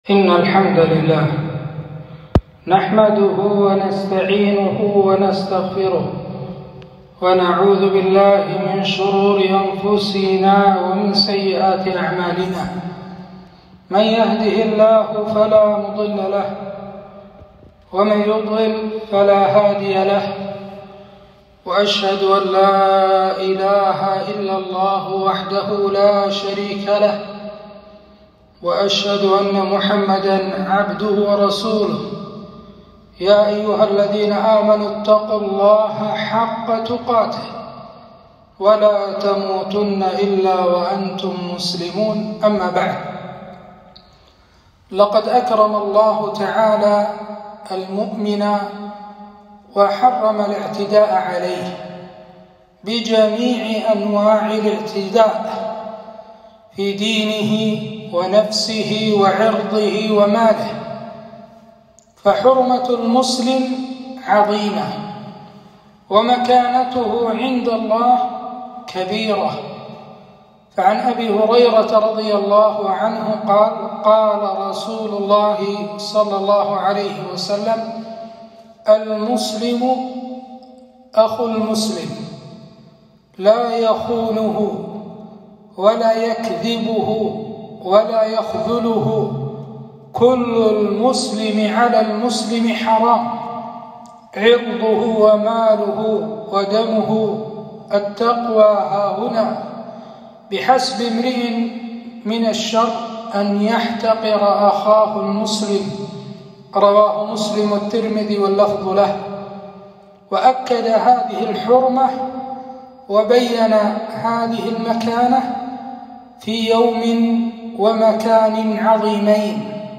خطبة - حرمة الدماء في الإسلام